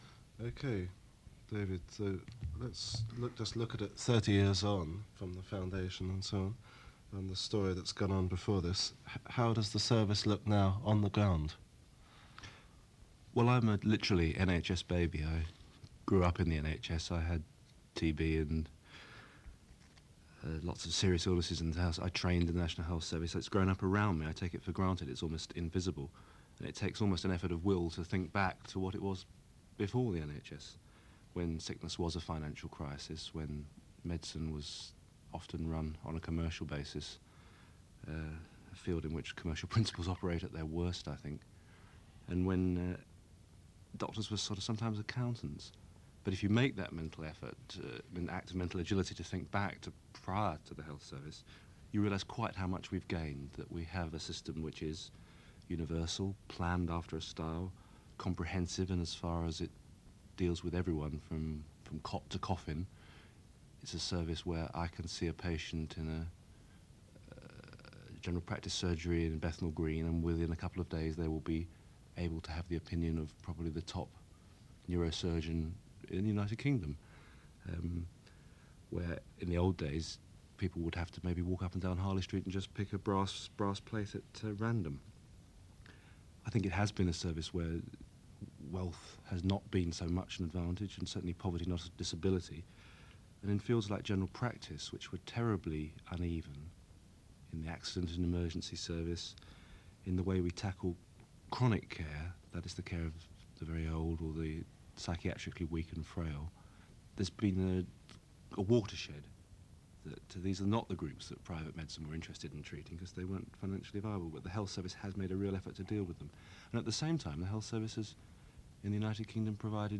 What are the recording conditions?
There is only one side of the cassette that has content.